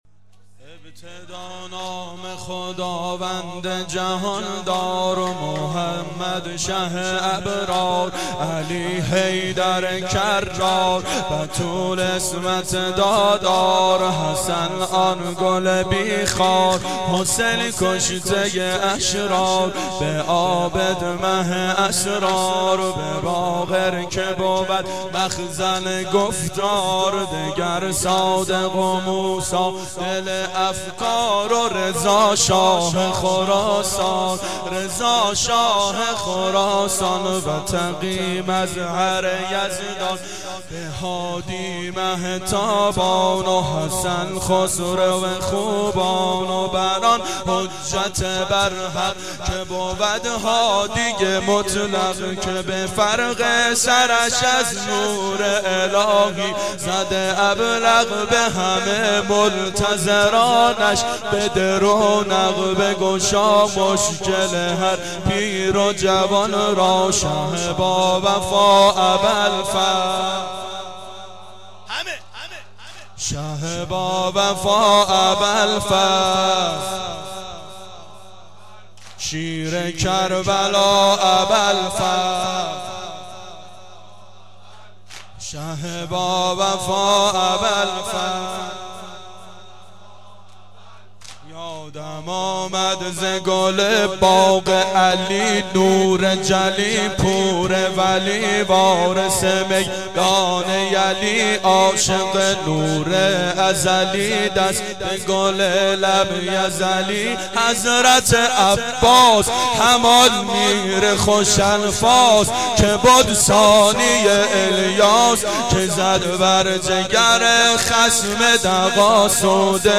بحر طویل
گلچین سال 1389 هیئت شیفتگان حضرت رقیه سلام الله علیها